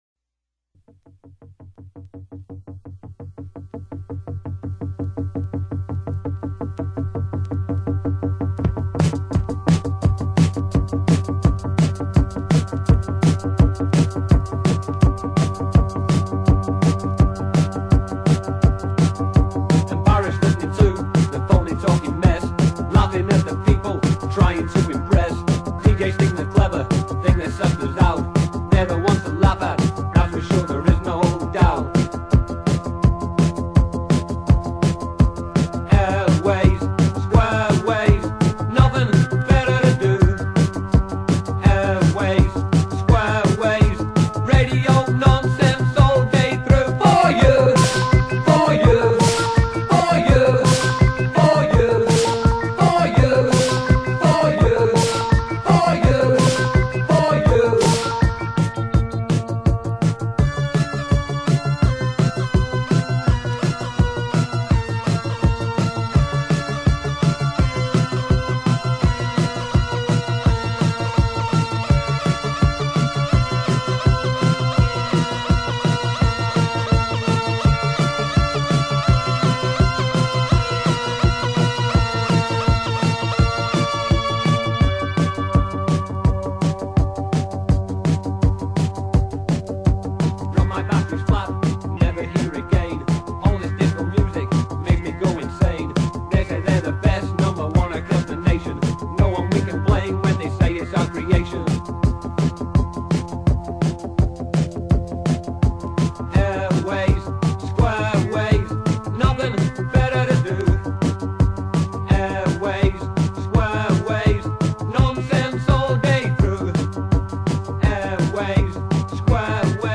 Un groupe de punk qui subitement, fait de la pop.